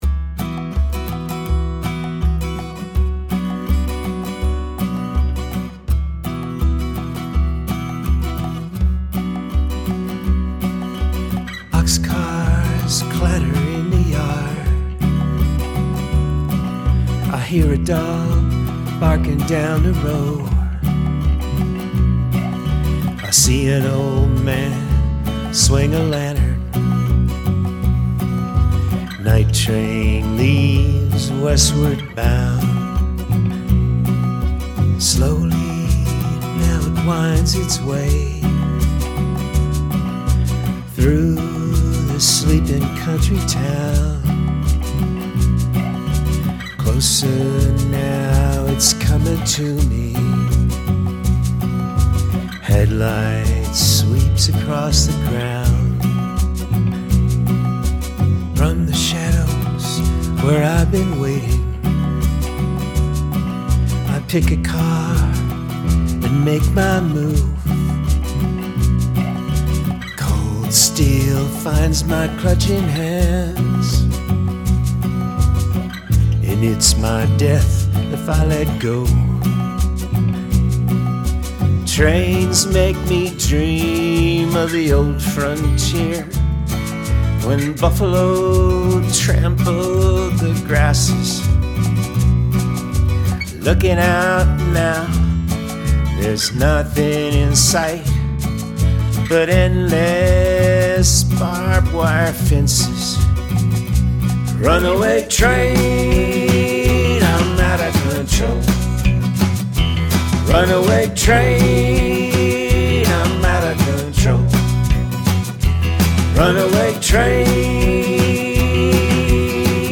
But I wanted to share these lo-fi recordings of works in progress with our community, because who knows if I’ll ever have time to polish them for mass consumption?
I started composing most of them with the electric bass